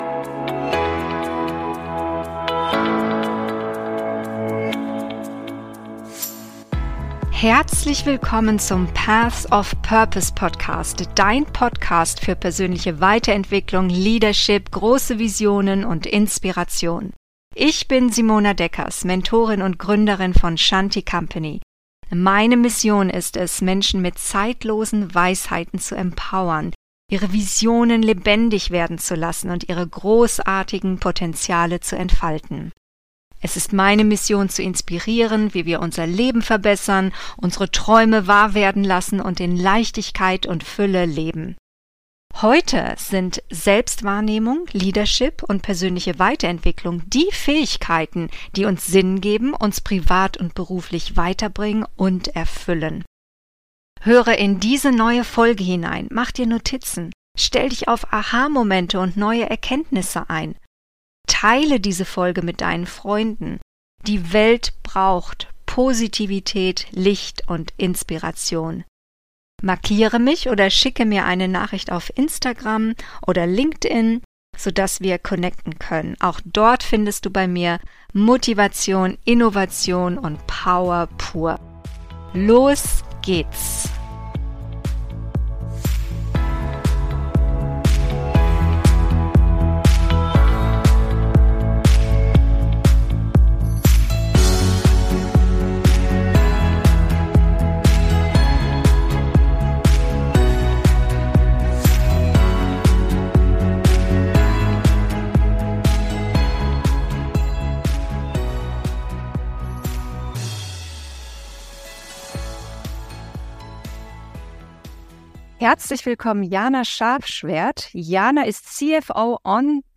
CFO Insights: Mit finanzieller Transparenz zum Erfolg – Interview